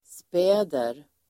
Uttal: [sp'ä:der]